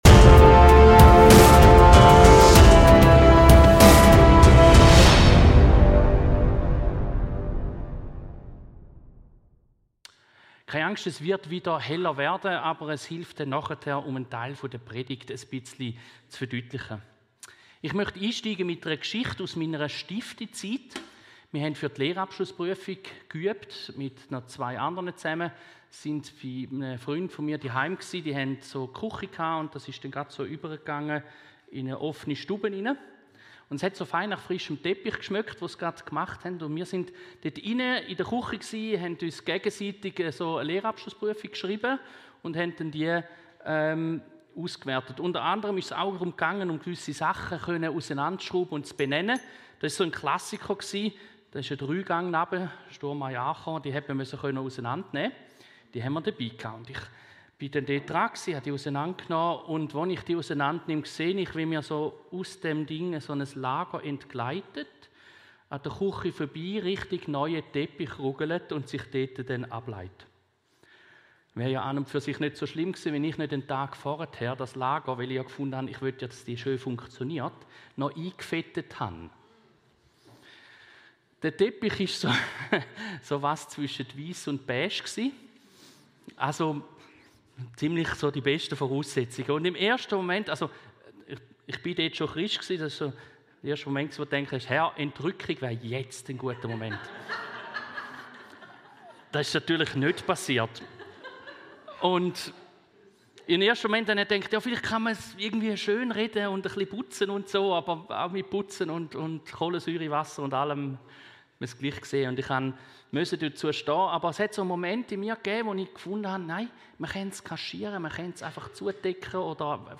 Predigt-Podcast
Audio-Predigten der Kirche Wigarten / Fällanden (CH)